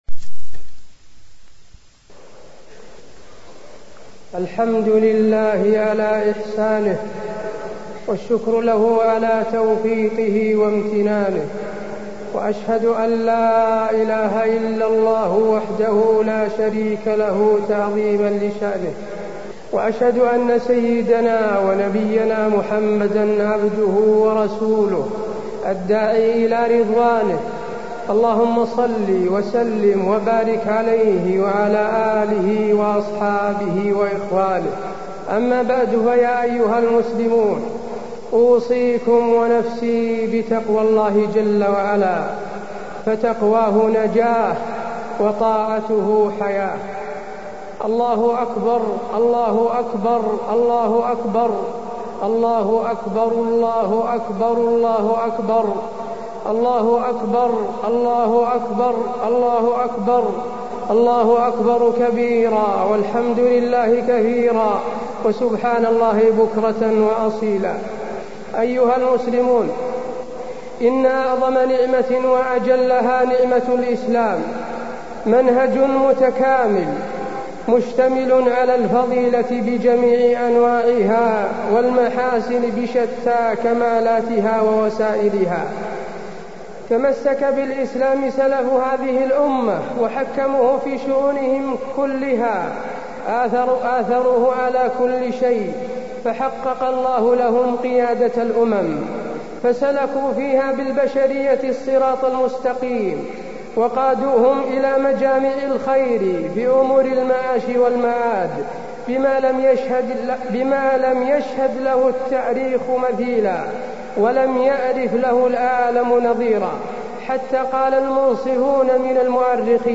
خطبة عيد الفطر- المدينة - الشيخ حسين آل الشيخ
المكان: المسجد النبوي